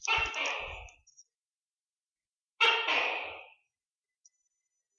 The Tokek (Tokay Gecko)
• Loud, repeated call: “TO-kay! TO-kay!” — often heard at night
Call of a Tokek (now you know where it got its name from) (source)
tokay-tokek-sound-gekko-bali.mp3